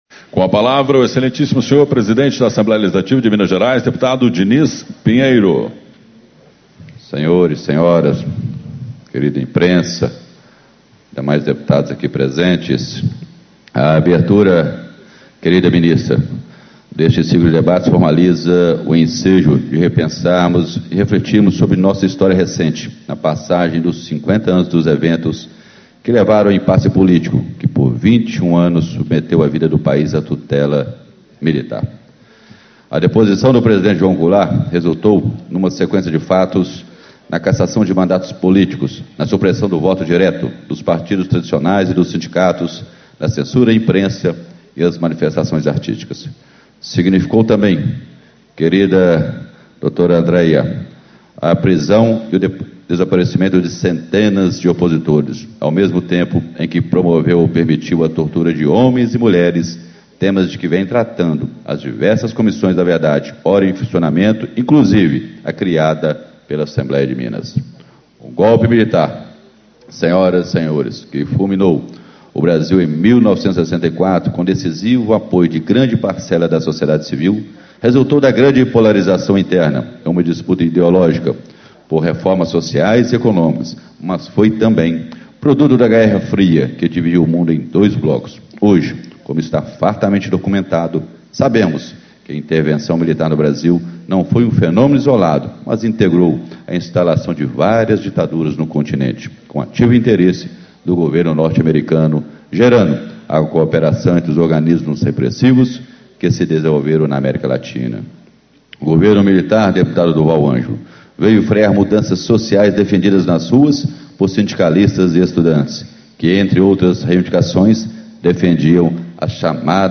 Abertura - Deputado Dinis Pinheiro, PP - Presidente da Assembleia Legislativa do Estado de Minas Gerais
Discursos e Palestras